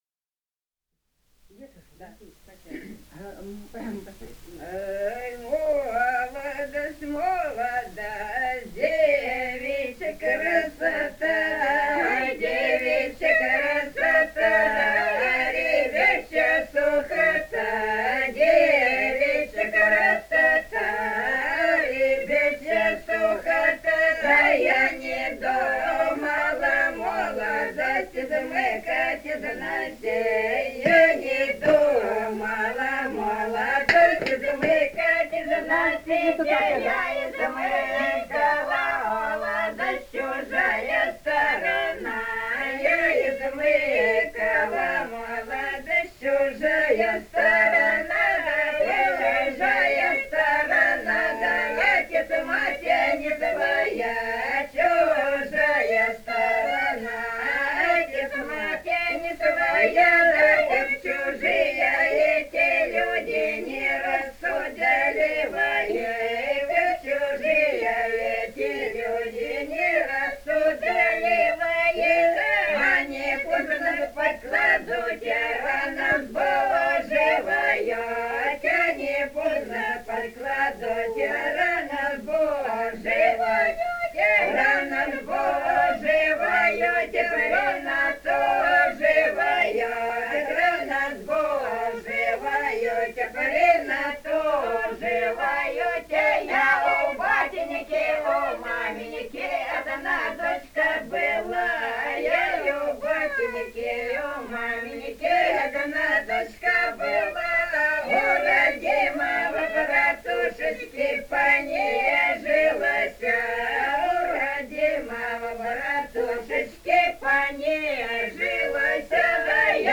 Этномузыкологические исследования и полевые материалы
«Ай, молодость, молодость» («на вечеринках и в Троицу»).
Ростовская область, г. Белая Калитва, 1966 г. И0940-09